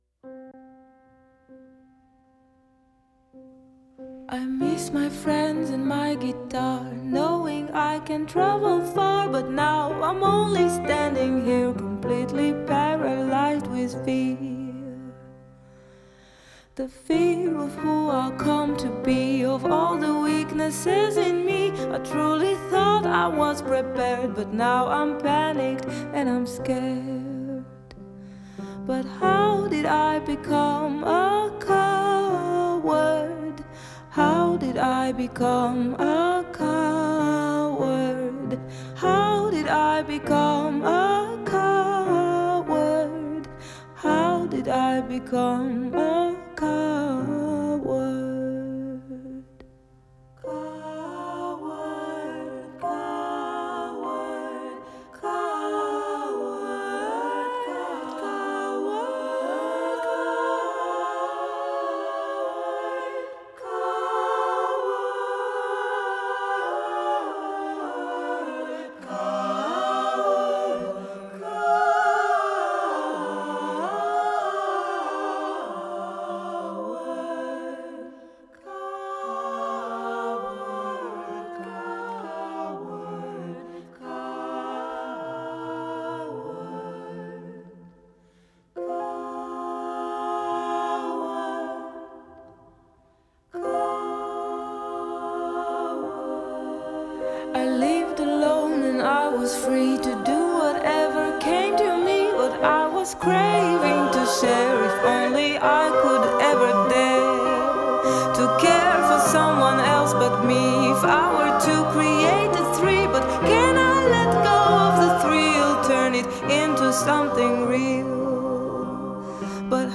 Жанр: Indie, Folk, Pop
Genre: Female vocalists, Indie, Folk, Pop